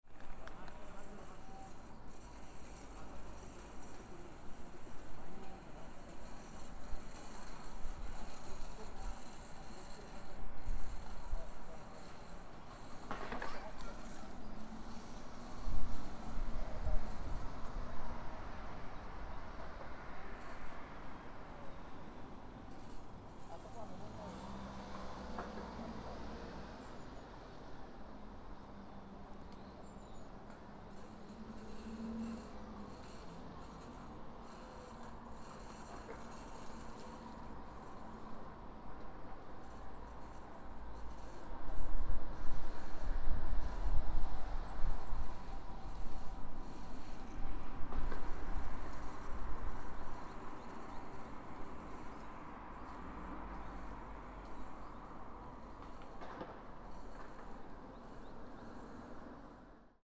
Temporary Storage Site for Radioactive Contamination
I could hear the twittering of some birds from the Mt. Shinobu, as usual. Also,I could not hear any sounds of living creatures from the temporary strage site, as usual.
A little girl ridding a bicycle passed through the road next to the temporary storage site. I could hear the sound of the bicycle.